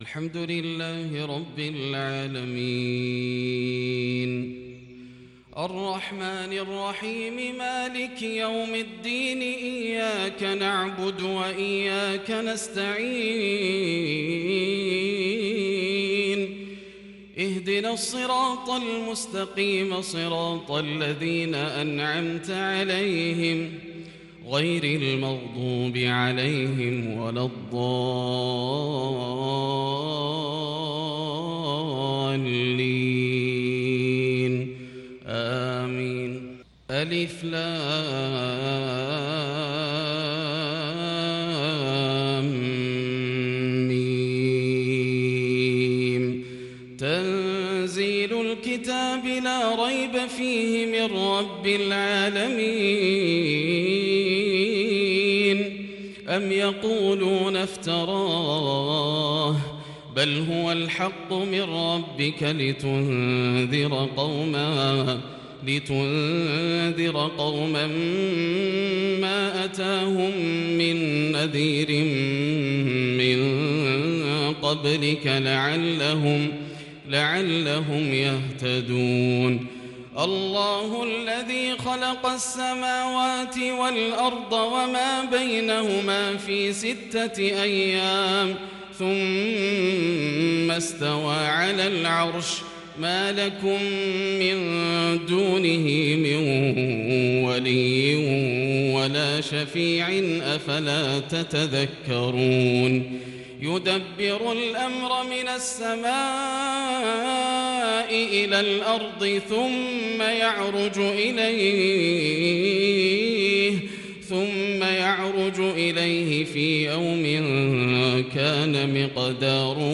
صلاة الفجر للشيخ ياسر الدوسري 22 صفر 1442 هـ
تِلَاوَات الْحَرَمَيْن .